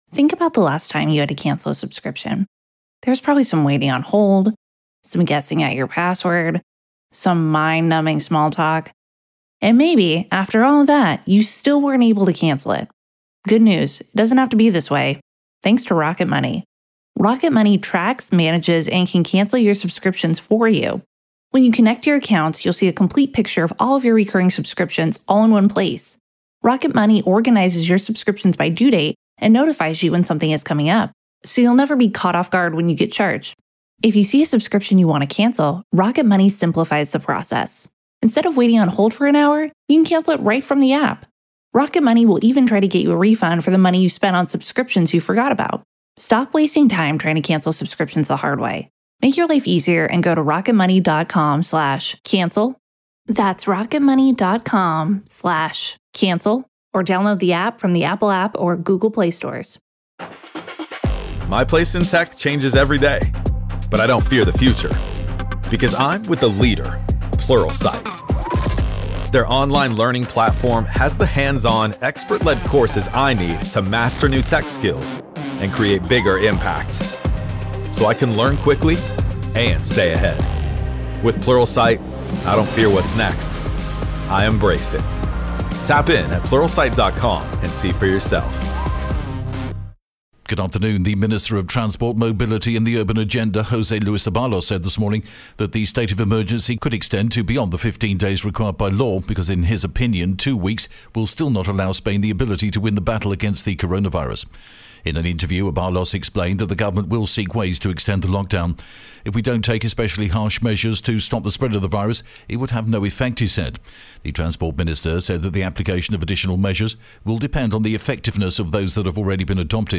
The latest Spanish news headlines in English: March 16th pm